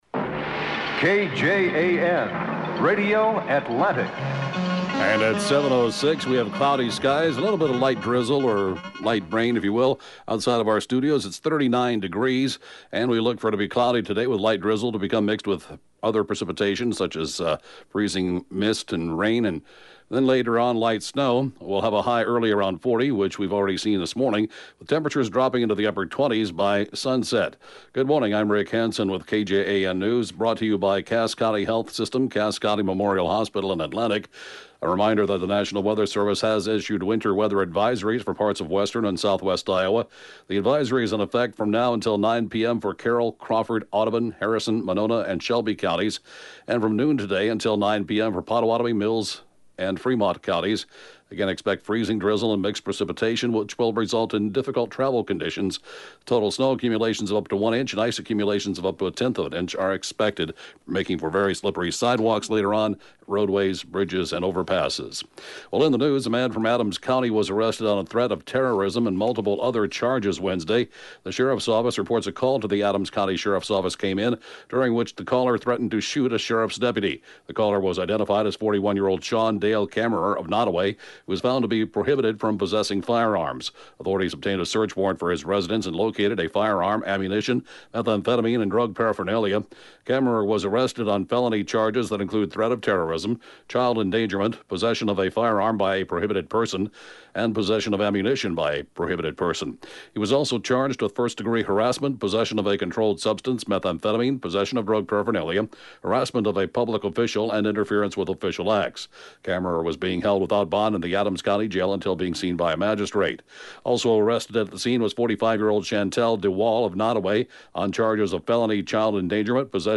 (Podcast) KJAN Morning News & funeral report, 12/21/2017